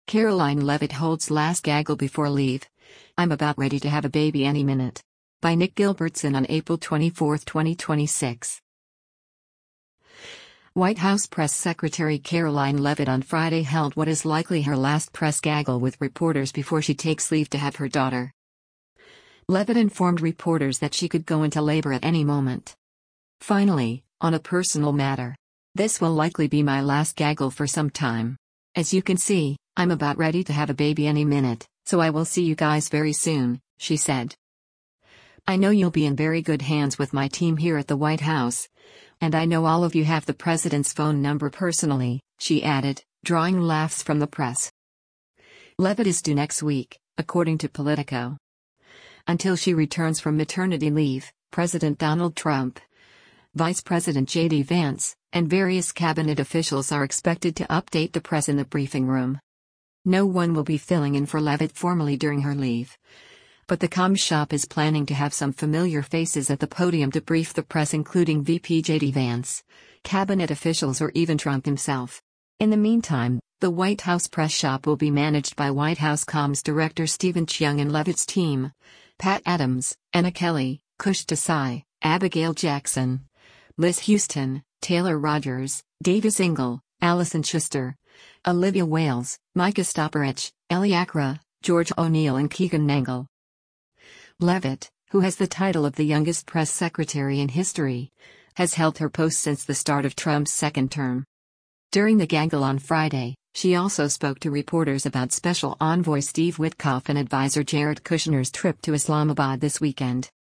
White House press secretary Karoline Leavitt on Friday held what is likely her last press gaggle with reporters before she takes leave to have her daughter.
“I know you’ll be in very good hands with my team here at the White House, and I know all of you have the president’s phone number personally,” she added, drawing laughs from the press.